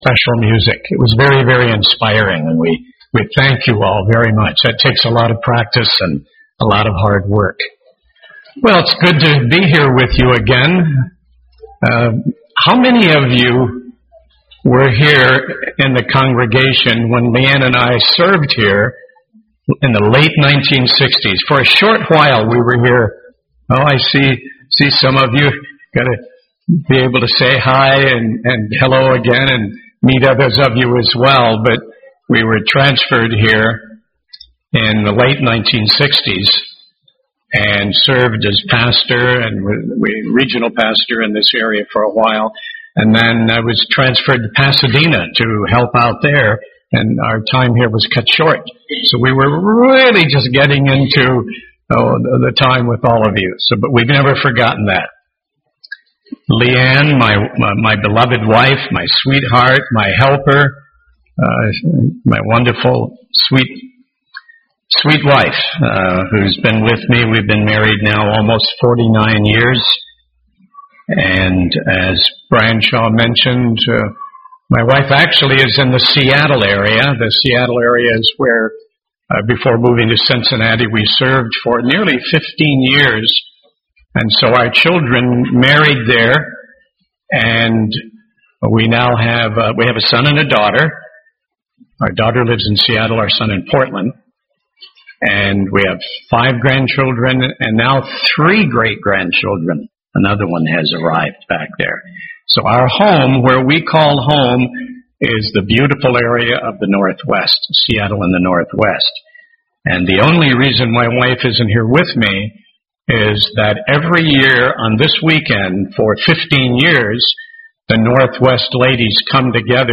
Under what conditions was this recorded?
Sermon given during Twin Cities Families for God Weekend.